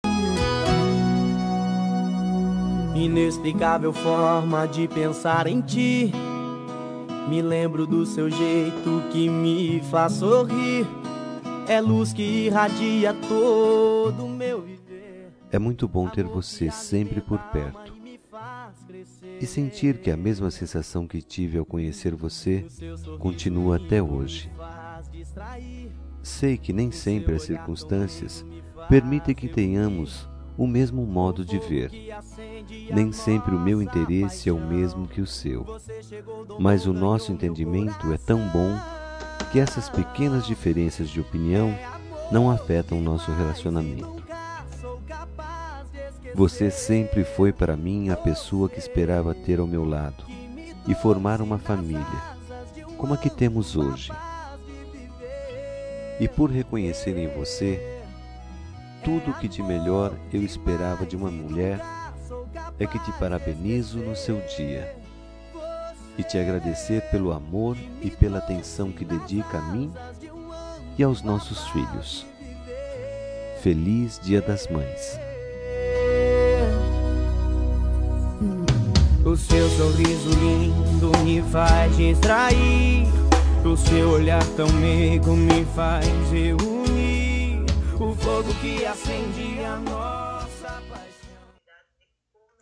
Dia das Mães – Para Esposa – Voz Masculina – Cód: 6532